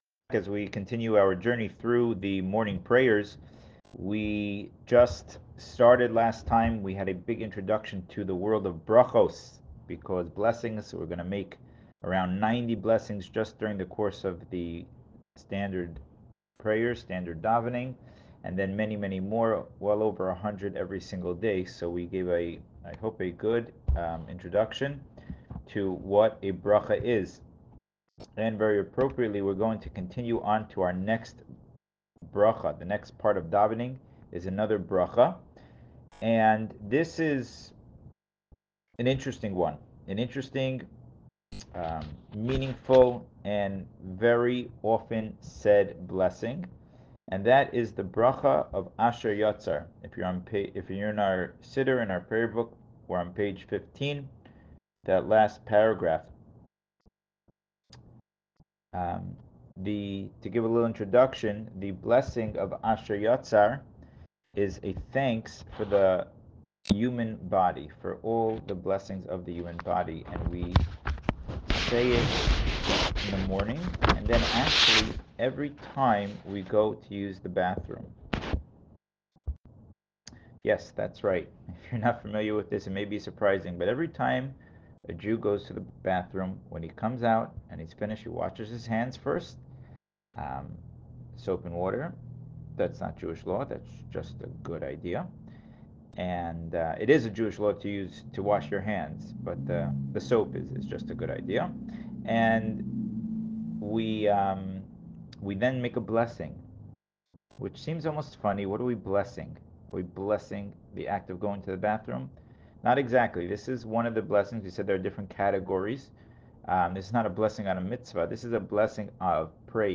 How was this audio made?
In this episode we read the blessing of asher yatzar. (I apologize for the technical glitch in the beginning, but the full episode is still intact).This blessing is to thank Hashem for our body and its proper functioning.